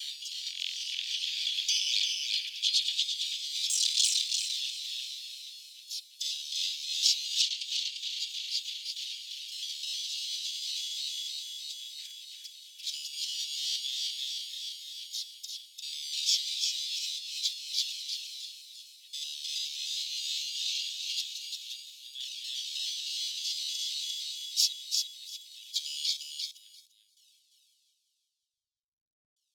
distortion of hearing